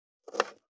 520切る,包丁,厨房,台所,野菜切る,咀嚼音,ナイフ,調理音,まな板の上,料理,
効果音厨房/台所/レストラン/kitchen食器食材